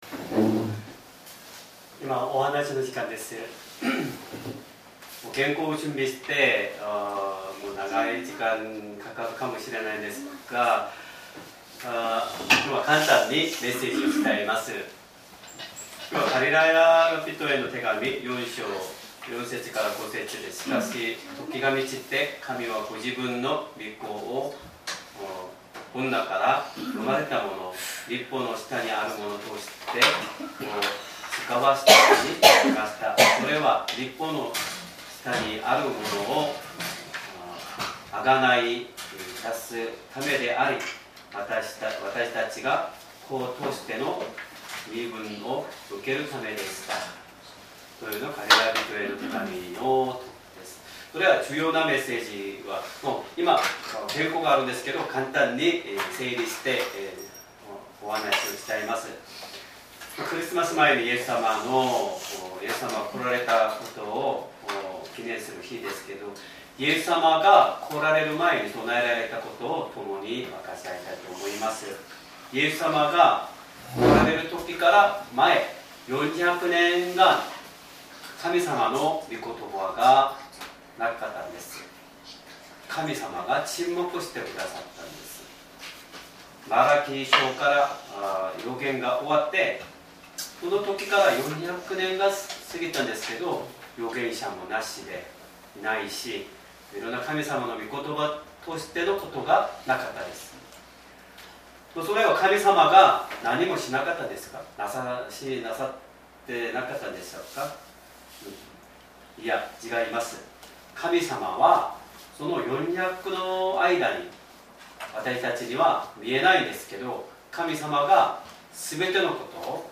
Sermon
2025年12月24日 クリスマス・イブ礼拝 説教 「イエス様の降誕のために備えられたこと 」 聖書 ガラテヤ人への手紙 4章 4～5 4:4 しかし時が満ちて、神はご自分の御子を、女から生まれた者、律法の下にある者として遣わされました。